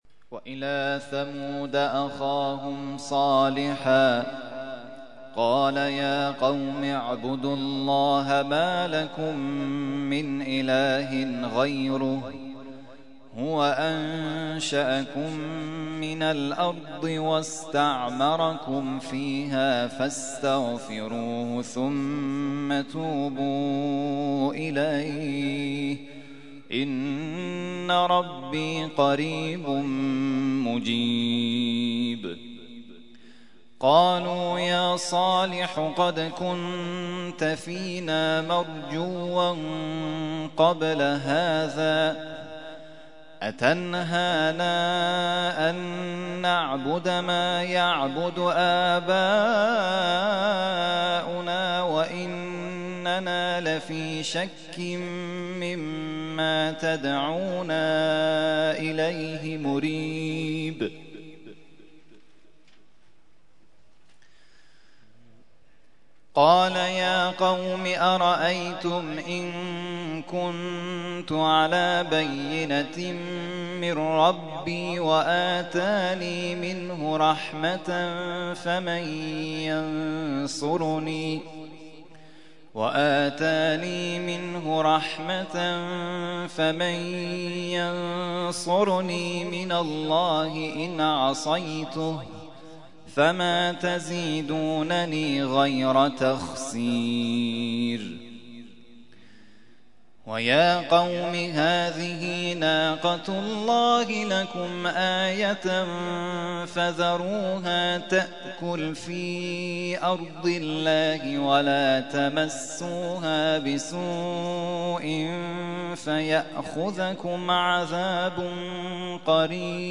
ترتیل خوانی جزء ۱۲ قرآن کریم در سال ۱۳۹۱